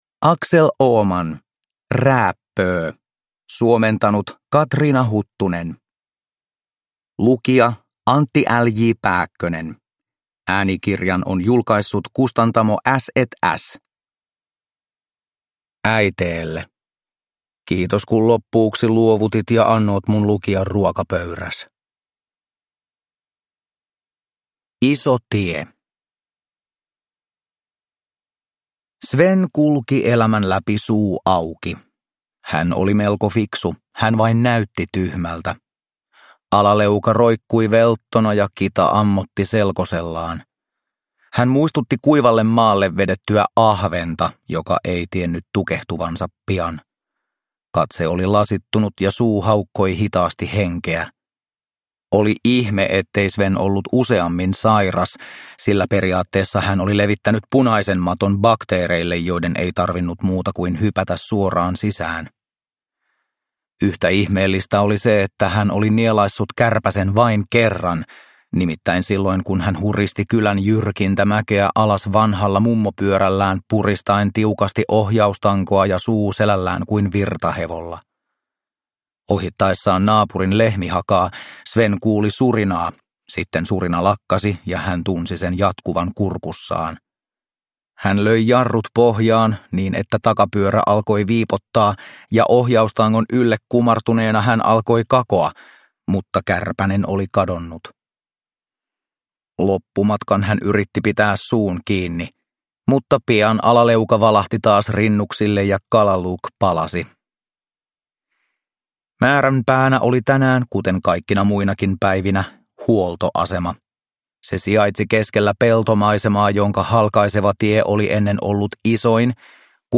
Rääppöö – Ljudbok
Maalaismaisemaan sijoittuvia novelleja värittää rouhea Pohjanmaan puheenparsi.
Uppläsare: Antti L.J. Pääkkönen